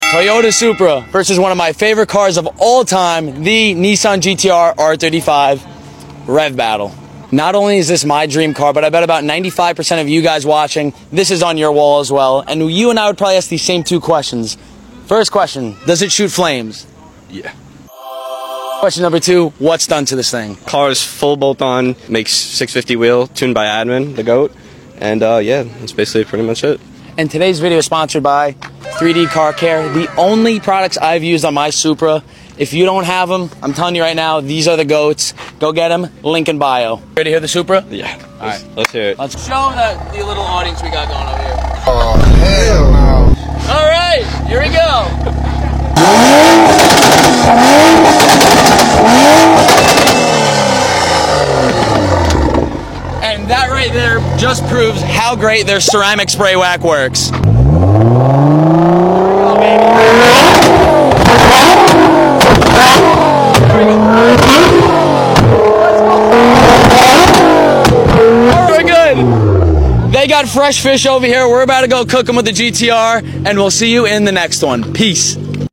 INSANE R35 GTR vs. MK5 Supra Rev Battle